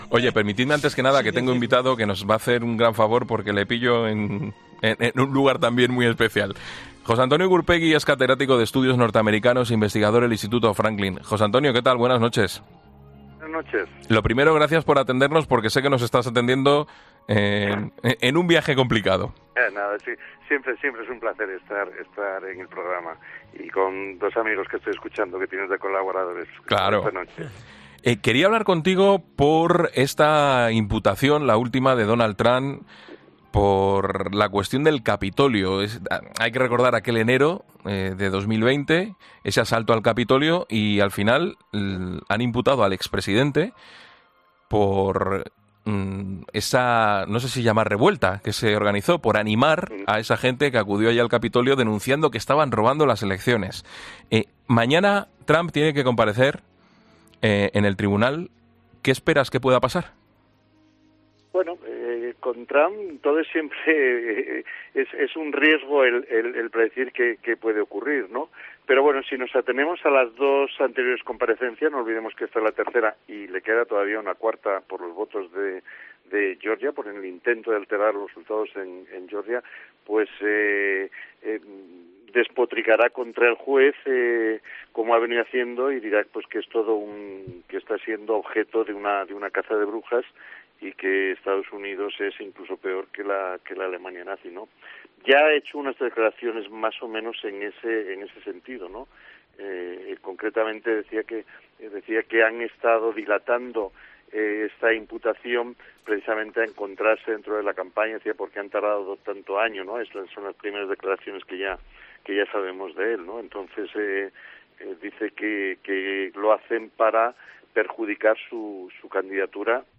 El catedrático de estudios norteamericanos, avisa en La Linterna de que la nueva imputación puede hacer perder al ex presidente apoyo republicano